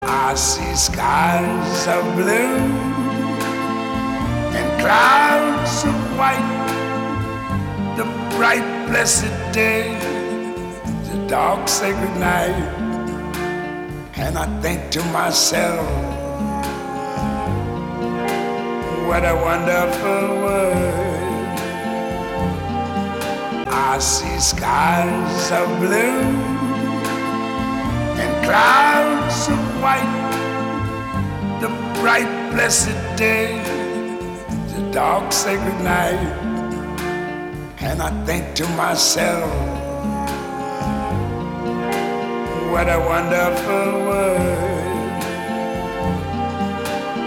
• Качество: 256, Stereo
джаз
блюз